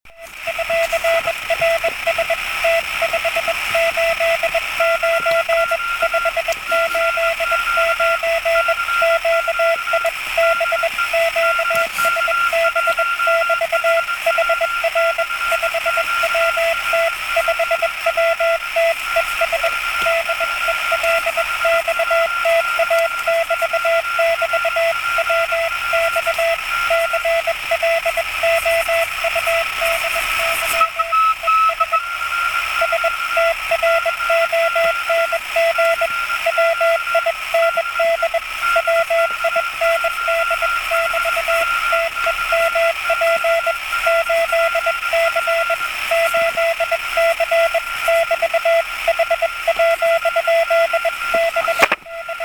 Приём на самоделке, может клму и будет интересно послушать...